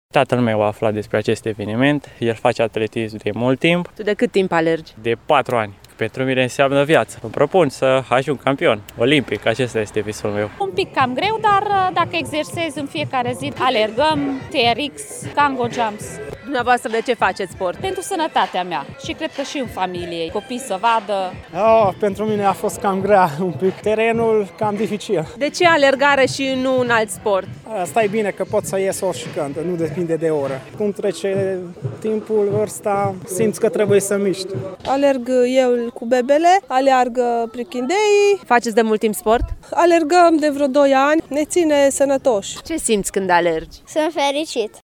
Evenimentul, unic în țară, ajuns la cea de-a patra ediție, a avut loc la Platoul Cornești din Tîrgu Mureș, în organizarea Asociației Copiilor și Tinerilor Diabetici Mureș.
Unii sunt alergători profesioniști, alții au participat cu întreaga familie pentru a da un exemplu pozitiv copiilor: